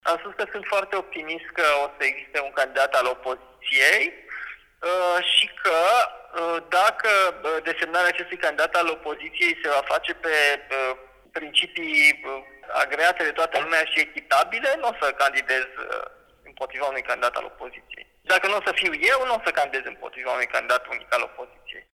„Am avut discuții exploratorii cu PNL, dar știți că nu veți scoate de la mine nimic până când ele nu se vor concretiza, dacă se vor concretiza într-un sens. Cu PLUS, nu, mai mult decât întâlniri ocazionale la diverse evenimente nu am avut”, a spus Dan în interviul pentru Europa Liberă.